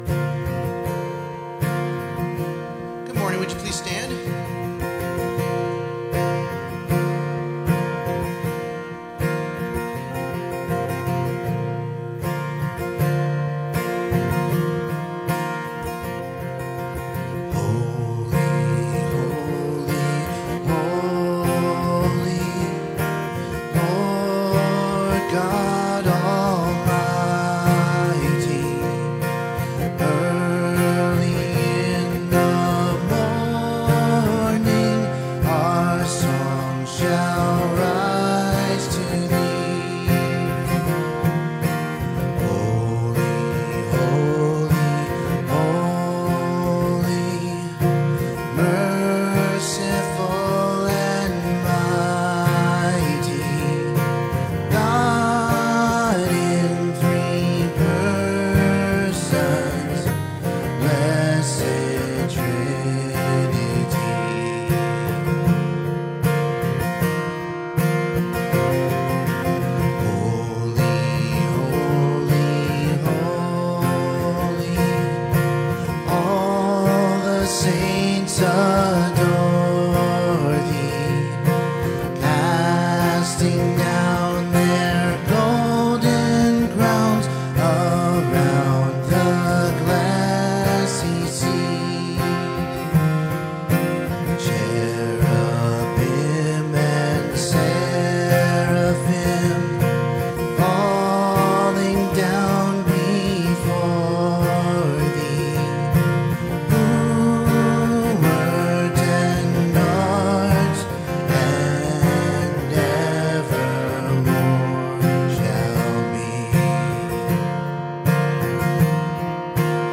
Relationships Service Type: Sunday Morning Youversion Event Next Steps